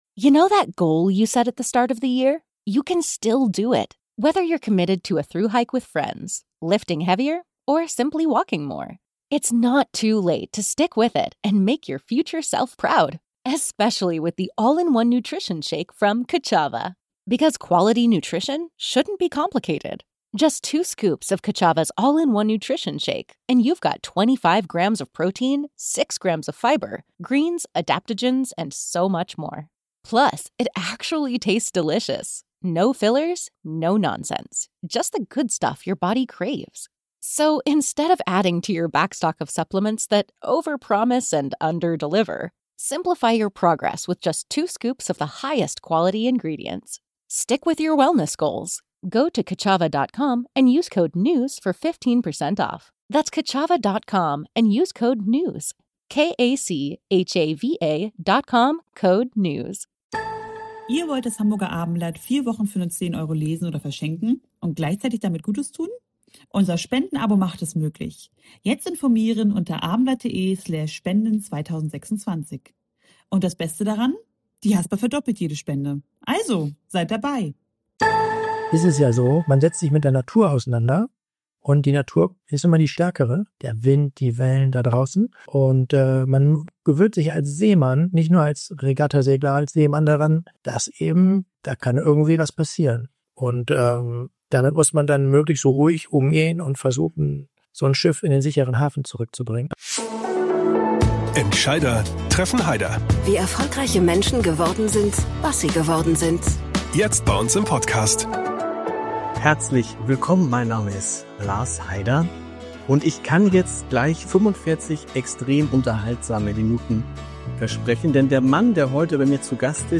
Nach Platz 5 und 12 greift Boris Herrmann erneut an. Der Profisegler spricht über die Lehren aus vergangenen Rennen, die unbändige Kraft der Natur und seine Vorbereitungen auf die härteste Regatta der Welt.